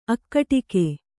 ♪ akkaṭike